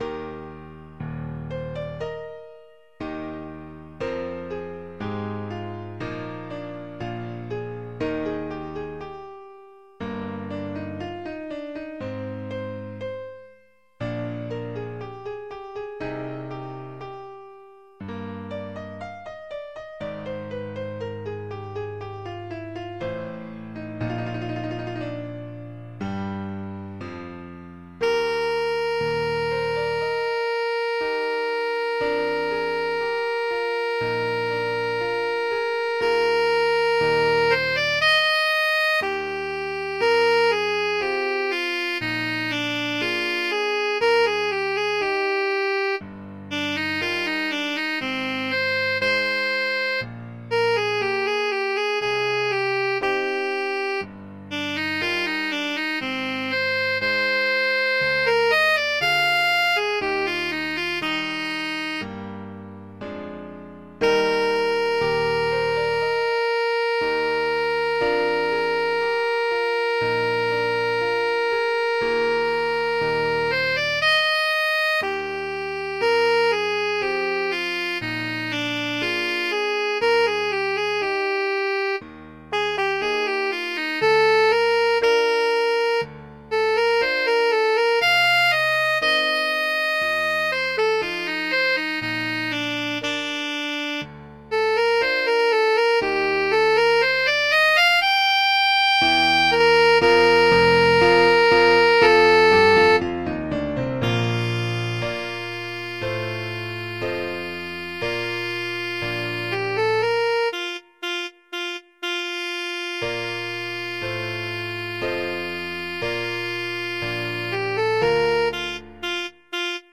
Alto Saxophone
Adagio
4/4 (View more 4/4 Music)
Classical (View more Classical Saxophone Music)